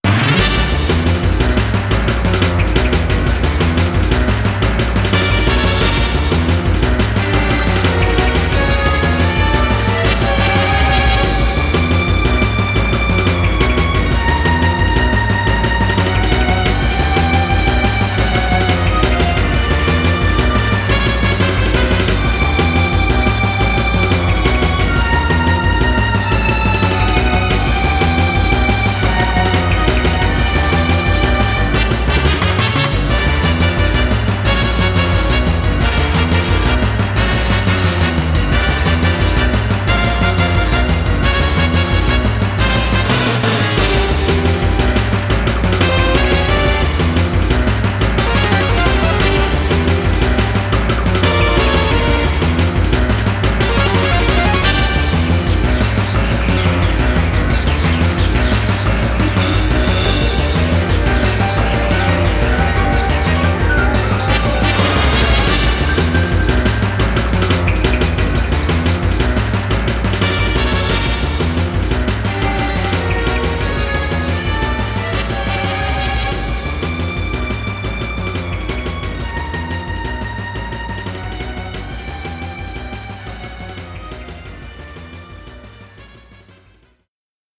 「荒々しさ」をイメージした戦闘曲です。
戦闘曲らしく、短く、違和感の無いループを意識しています。
いきなり来るので音量注意です！